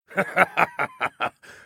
laughter_02